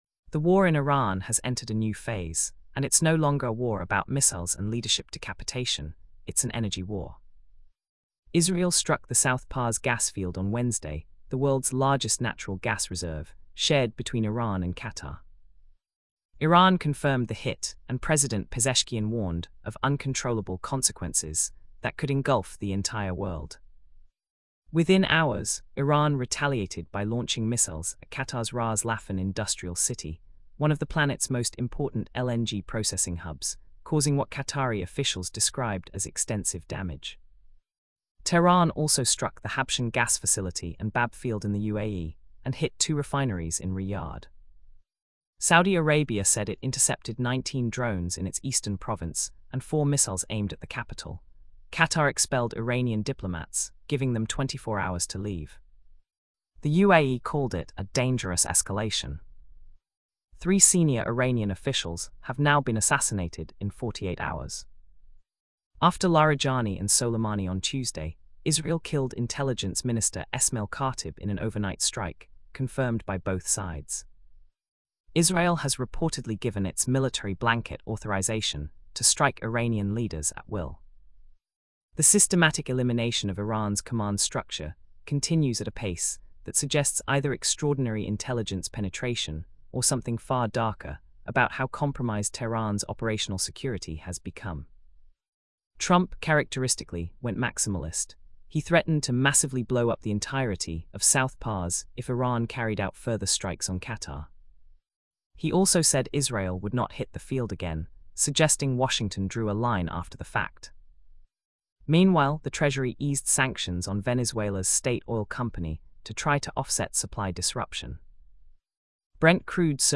Listen to this briefing Download audio The war in Iran has entered a new phase, and it's no longer a war about missiles and leadership decapitation — it's an energy war.